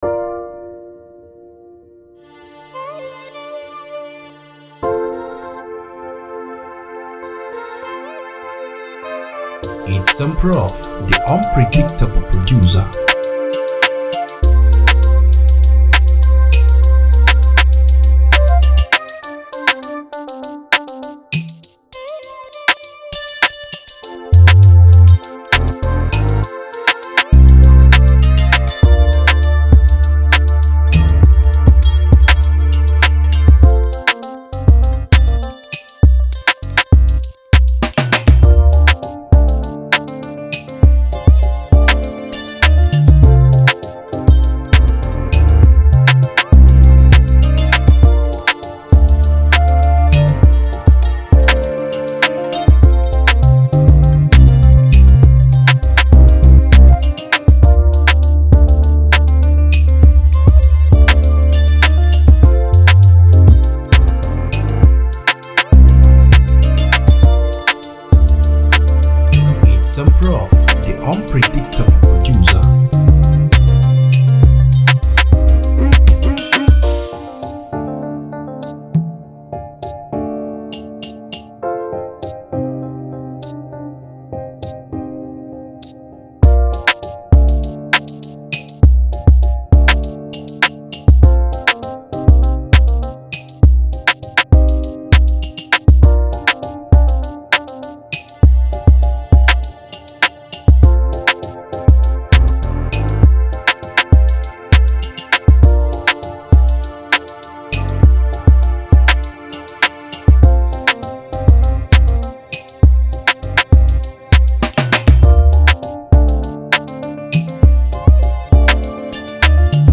fire instrumental
it’s a pure Afrobeat sound ready to be used.